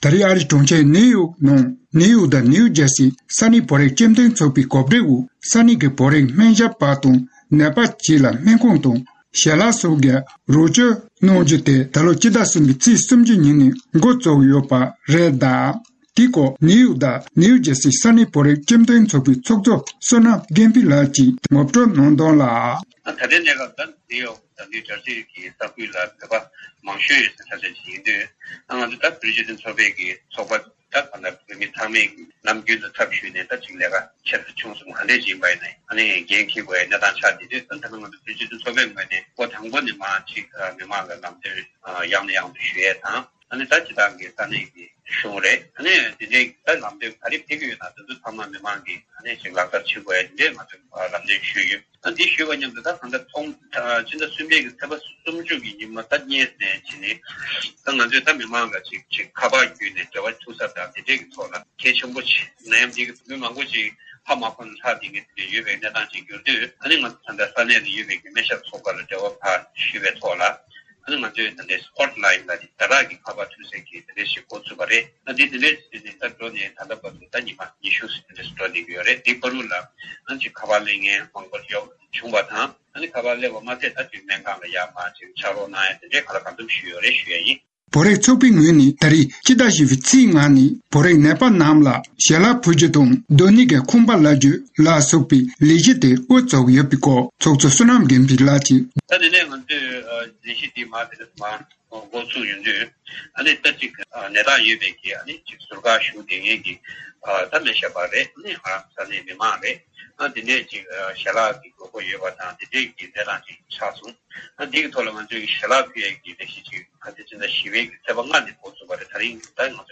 ཕྱོགས་སྒྲིག་ཀྱིས་སྙན་སྒྲོན་ཞུས་པ་གསན་རོགས་ཞུ།།